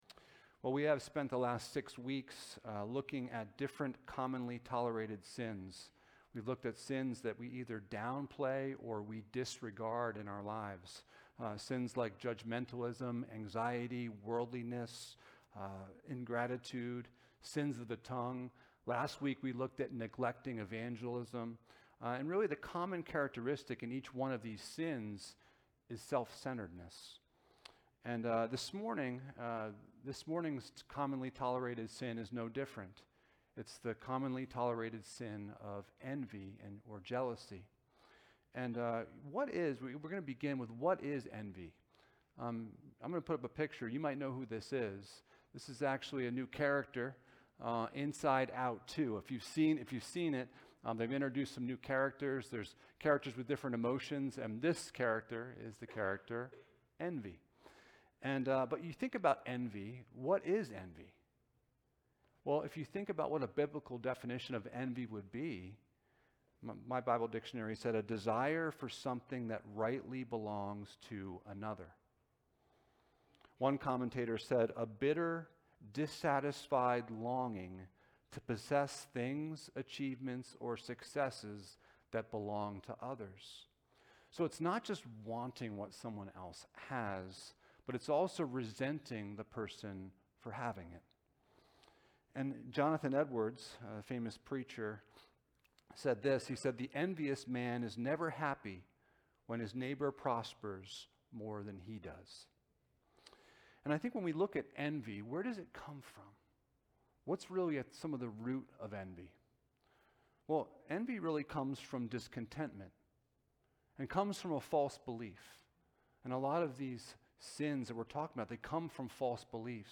Passage: James 3:13-18 Service Type: Sunday Morning